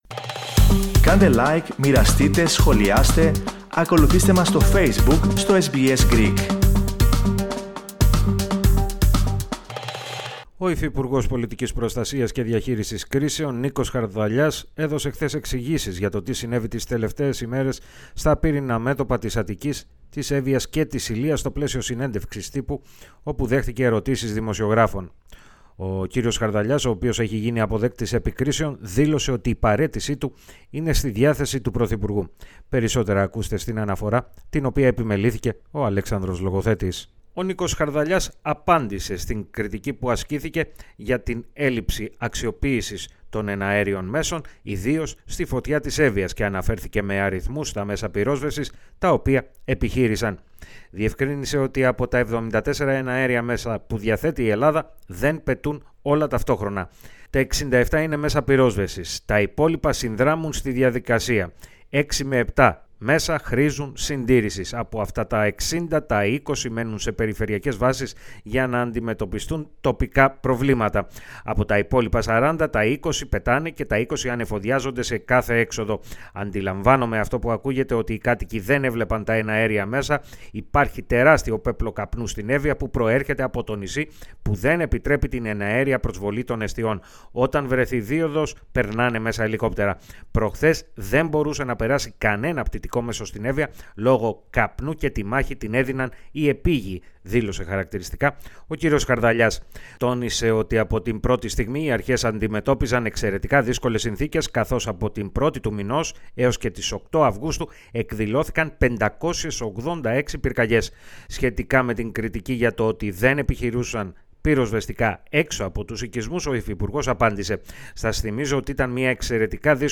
Ο υφυπουργός Πολιτικής Προστασίας και Διαχείρισης Κρίσεων, Νίκος Χαρδαλιάς, έδωσε, χθες, εξηγήσεις για το τι συνέβη τις τελευταίες μέρες στα πύρινα μέτωπα της Αττικής, της Εύβοιας και της Ηλείας, στο πλαίσιο Συνέντευξης Τύπου, όπου δέχτηκε ερωτήσεις δημοσιογράφων.